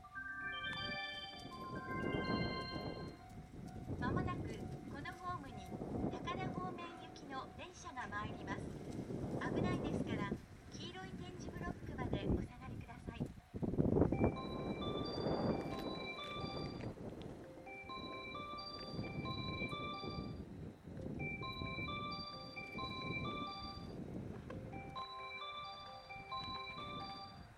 この駅では接近放送が設置されています。
接近放送普通　高田行き接近放送です。